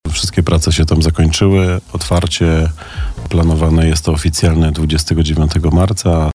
Informację tę przekazał nam dzisiaj rano wiceprezydent miasta Przemysława Kamiński, podczas audycji Gość Radia Bielsko.